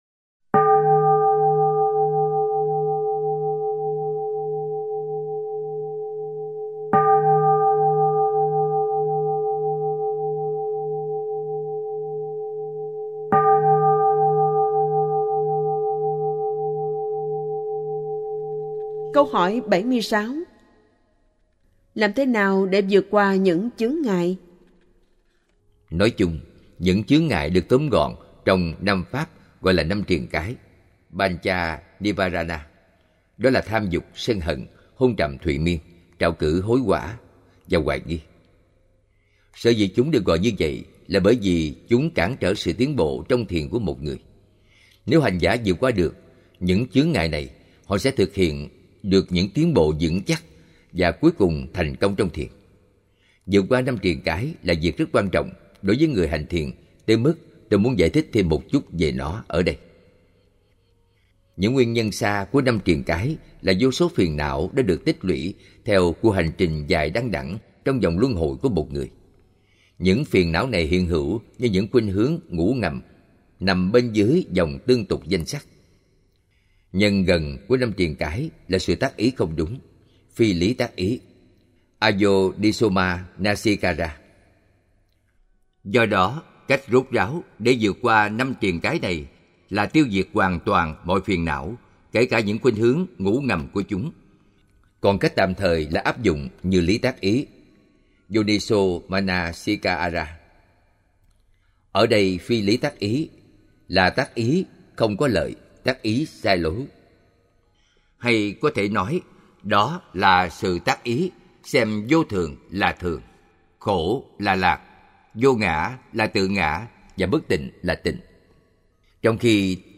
17 Hỏi và đáp phần 3 (tiếp theo – hết).mp3